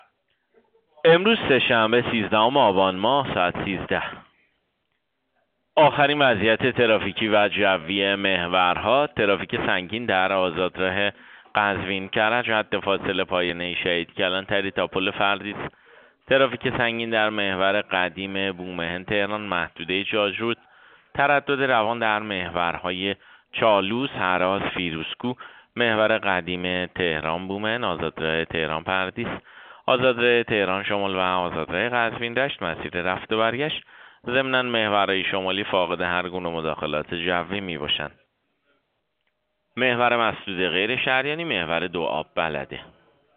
گزارش رادیو اینترنتی از آخرین وضعیت ترافیکی جاده‌ها ساعت ۱۳ سیزدهم آبان؛